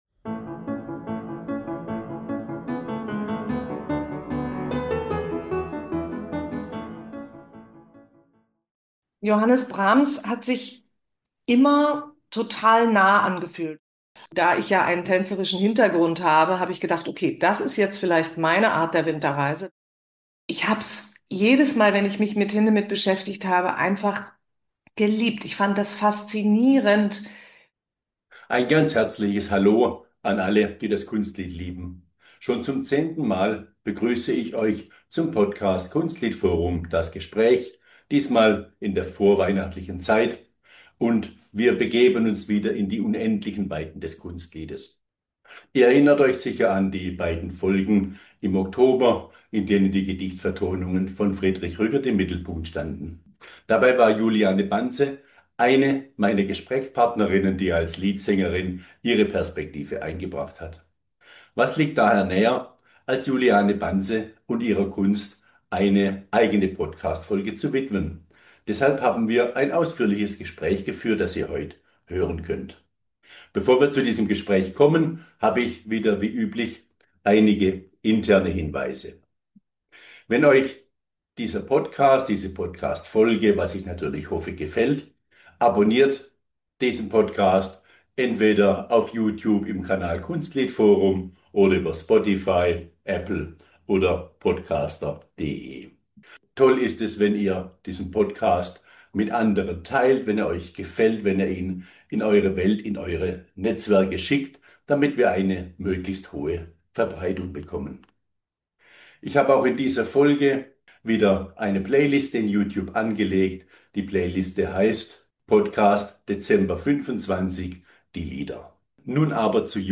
Kunstliedforum - Das Gespräch mit Juliane Banse über das Lied in der Moderne und vieles andere ~ Kunstliedforum - Das Gespräch Podcast
Seit vielen Jahren ist sie eine der führenden Liedsängerinnen und hat sich beispielsweise herausragend des Liedschaffens von Paul Hindemith angenommen. Dies uns vieles mehr ist Gegenstand unseres ausführlichen Gesprächs. 00:00 Anmoderation...